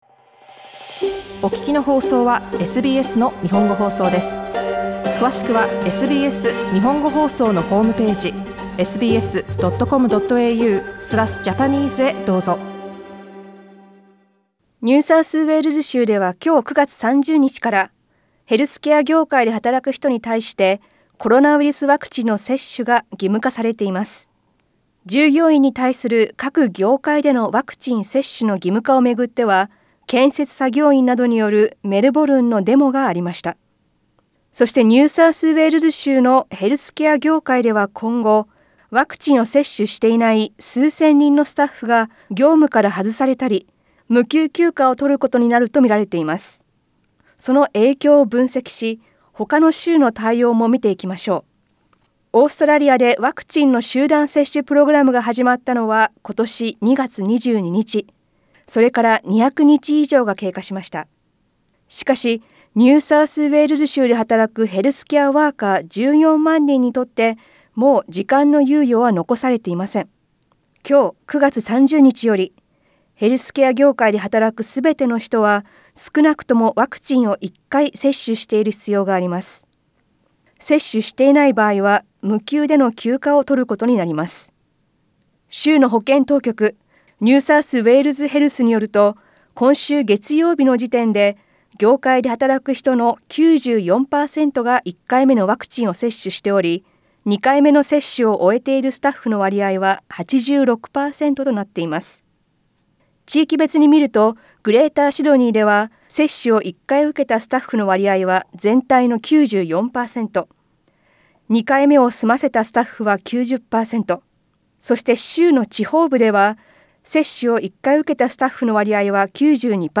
音声リポートではその影響を分析し、ほかの州の対応にも触れます。